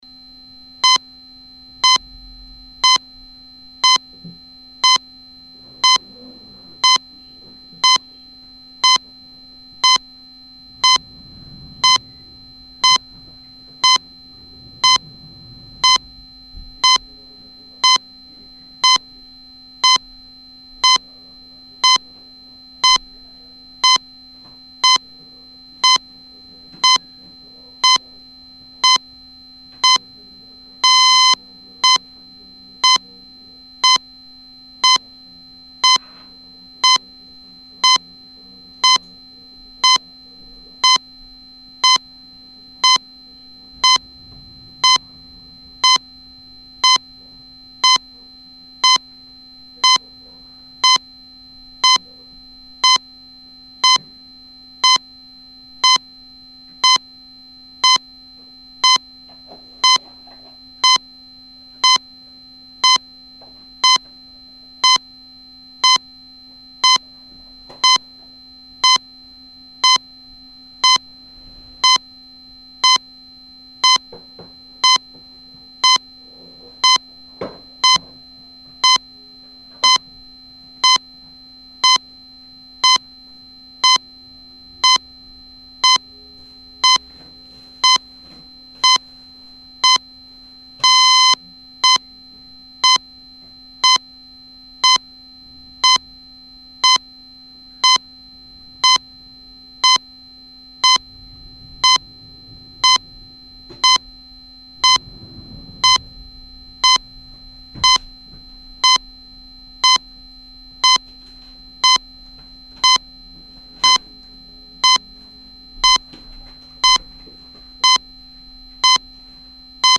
fastron_zegar.mp3